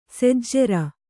♪ sejjera